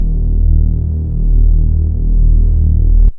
YM - D# (USB Bass).wav